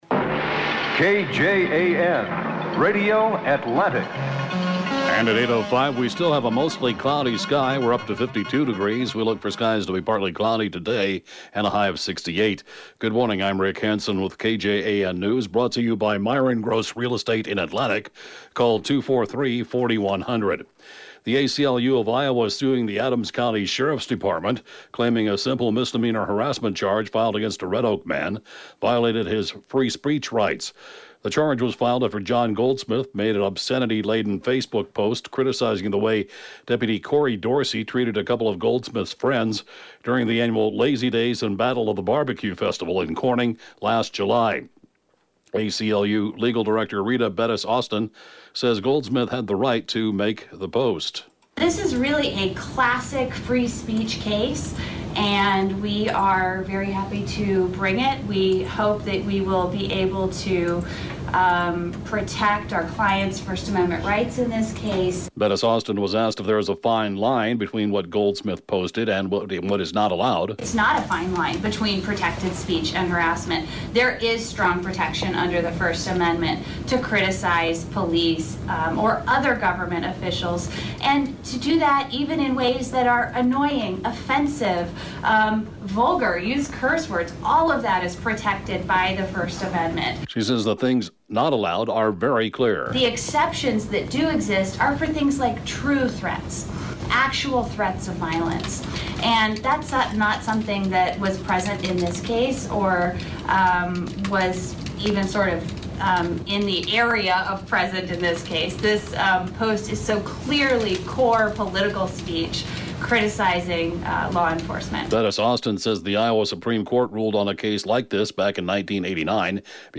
(Podcast) KJAN Morning News & Funeral report, 6/27/19